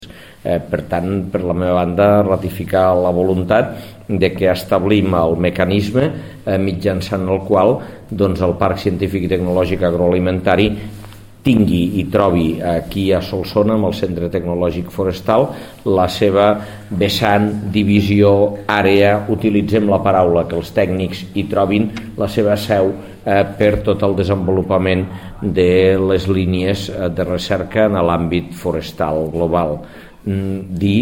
(adjuntem TALL DE VEU).
tall-de-veu-angel-ros